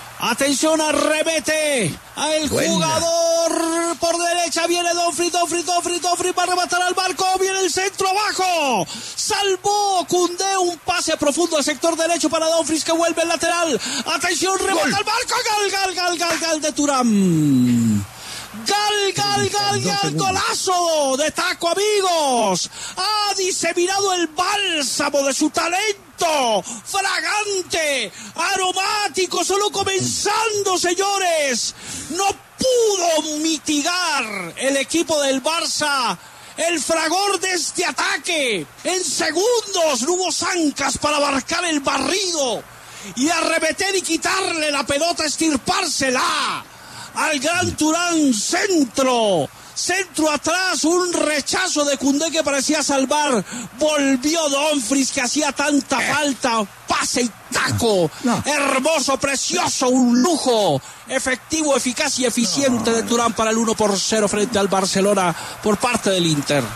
“No pudo mitigar el Barcelona el fragor de este ataque”: Así narró Martín De Francisco gol del Inter
Hernán Peláez y Martín de Francisco, en una transmisión especial, narran el partido que abre el telón de la segunda semifinal de la UEFA Champions League.